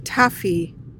PRONUNCIATION: (TAF-ee) MEANING: noun: 1.